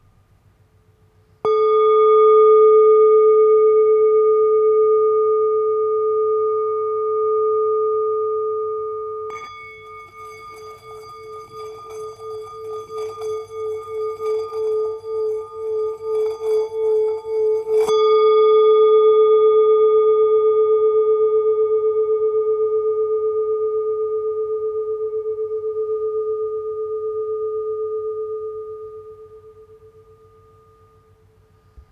A Note 5.5″ Singing Bowl